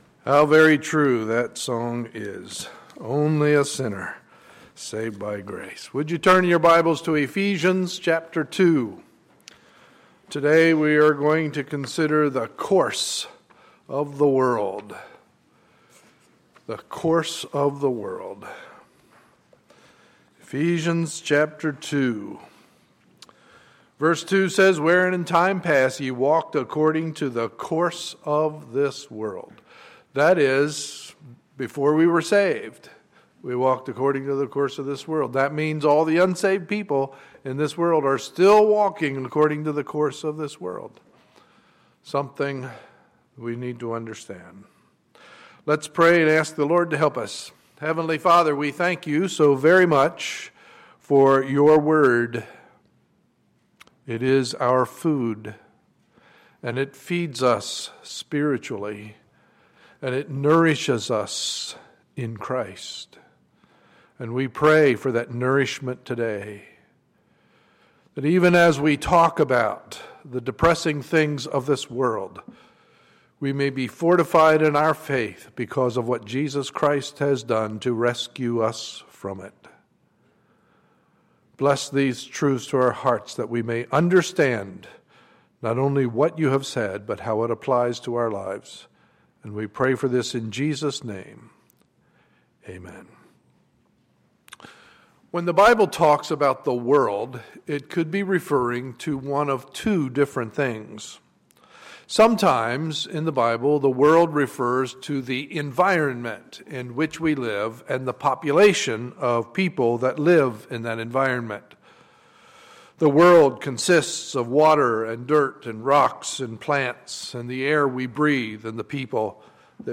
Sunday, August 11, 2014 – Morning Service